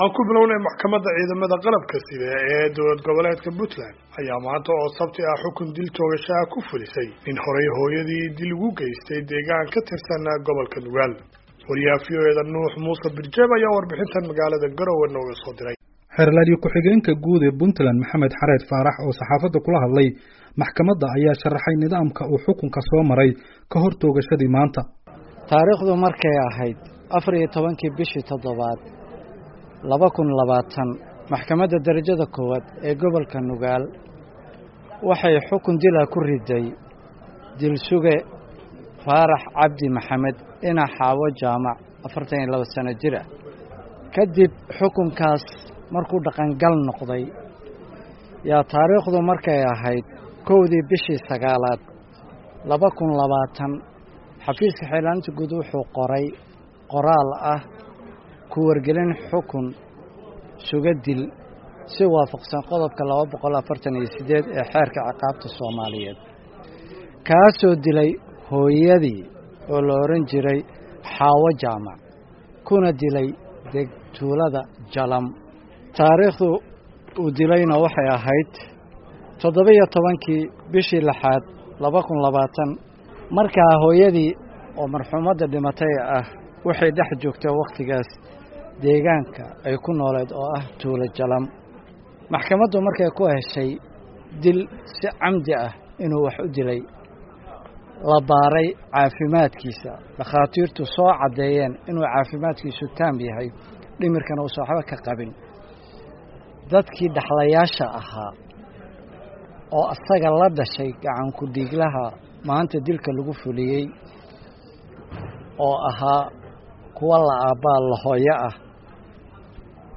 GAROWE —